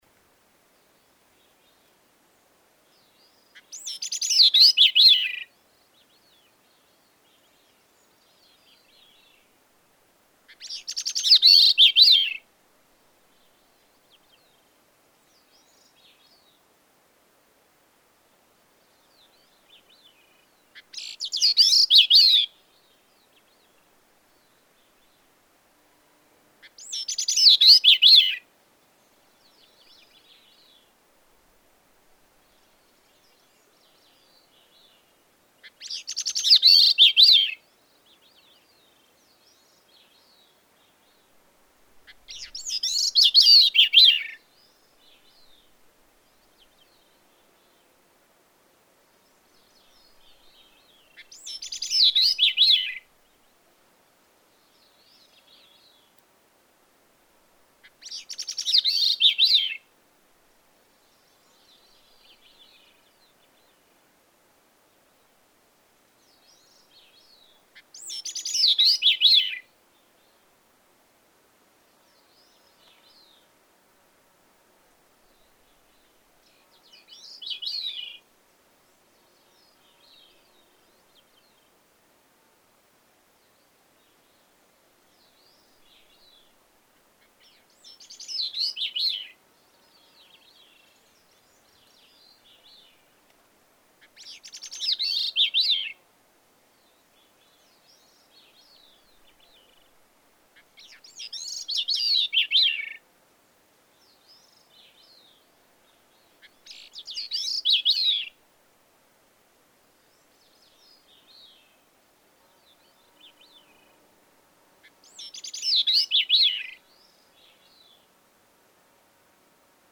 Gray-cheeked thrush
Beginning about 12:30 a.m., about half an hour after sunset, this gray-cheeked thrush sang through the short night and well past sunrise (about 3:30 a.m.). Stay with him through his three-minute respites from 24:20 to 27:20 and 50:30 to 53:50, for a little over an hour total.
Denali Highway, Alaska.
533_Gray-cheeked_Thrush.mp3